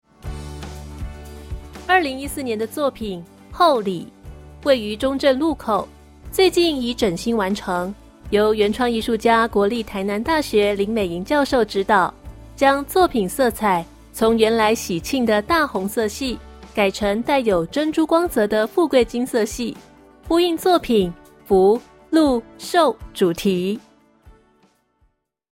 中文語音解說